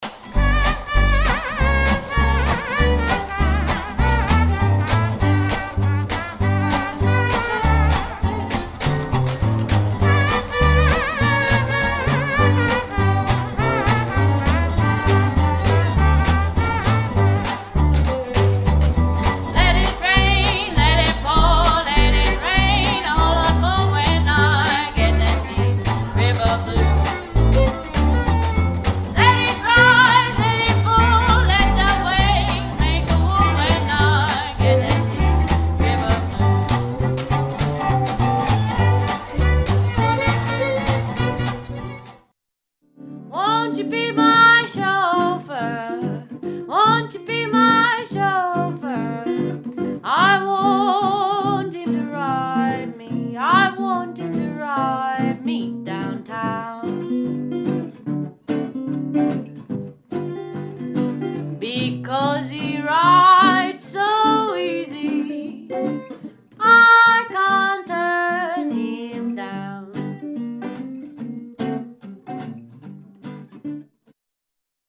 au jazz et au blues des origines
chant, guitare, washboard
clarinette
trombone
contrebasse
batterie